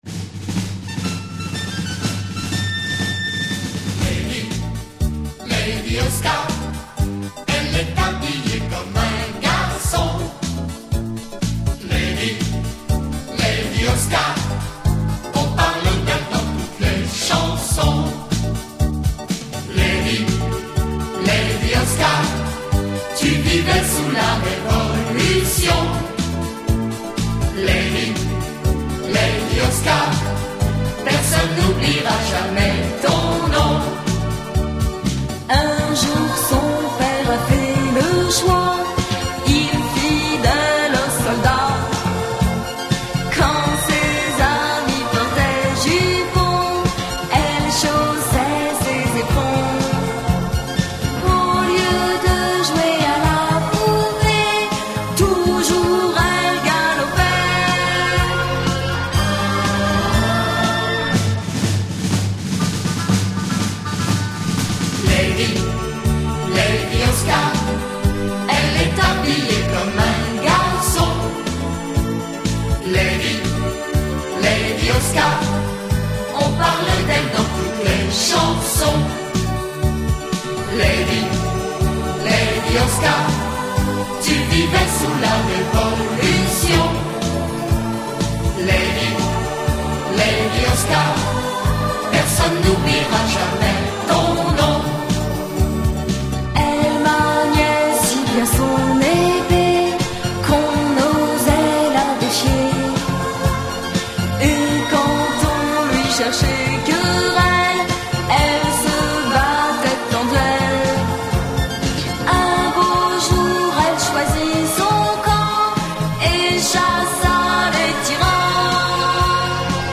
Générique français d'ouverture